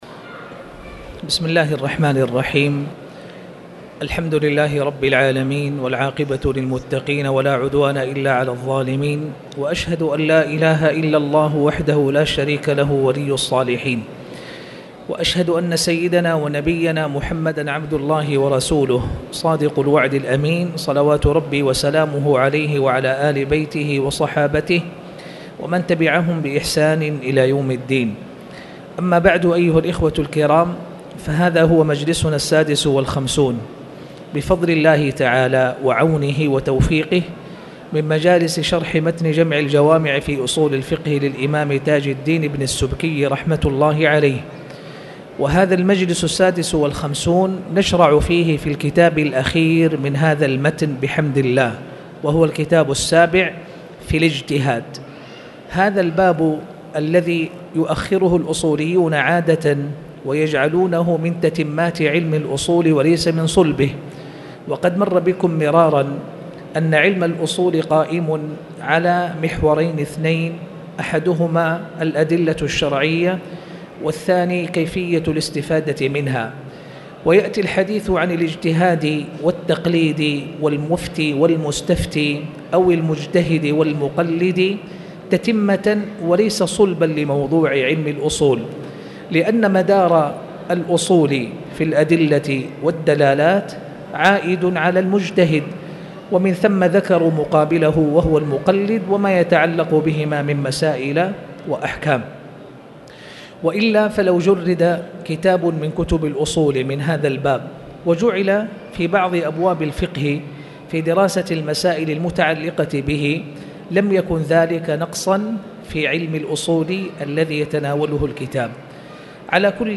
تاريخ النشر ٢ جمادى الآخرة ١٤٣٨ هـ المكان: المسجد الحرام الشيخ